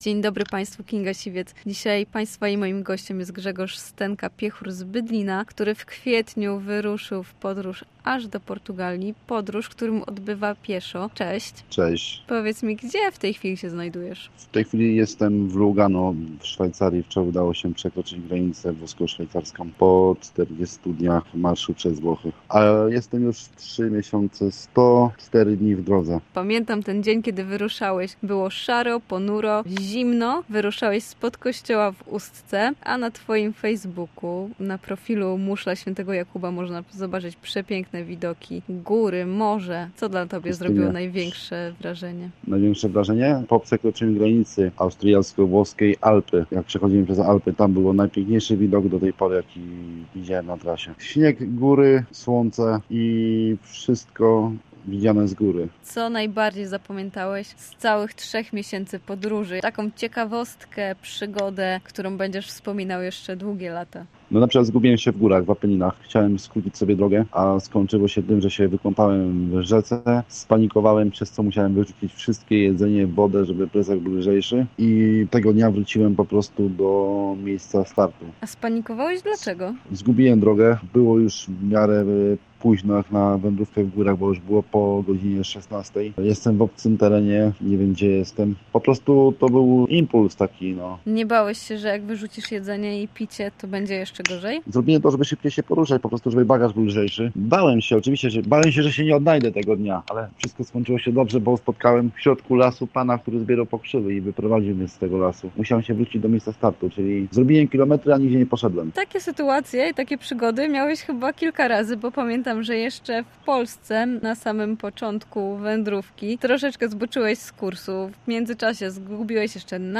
Telefonicznie był gościem Studia Słupsk.